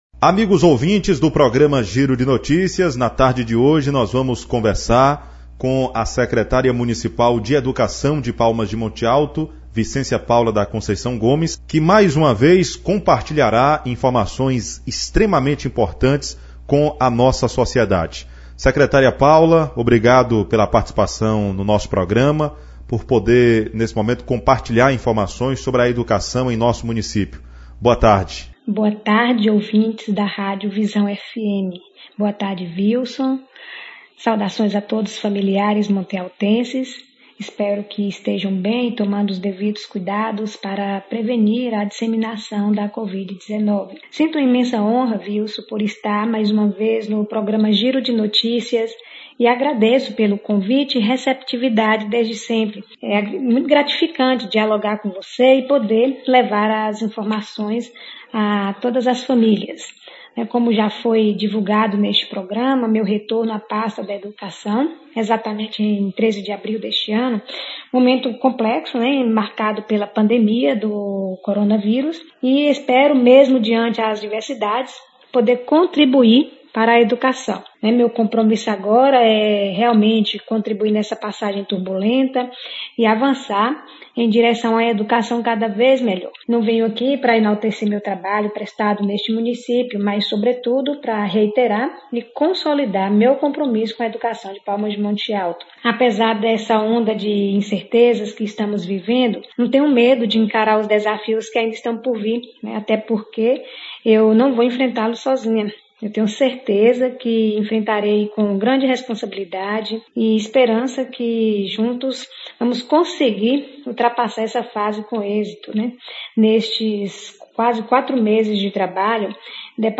Em entrevista à Rádio Visão FM, Secretária de Educação de Palmas de Monte Alto fala sobre retorno à pasta, atividades remotas e volta às aulas; confira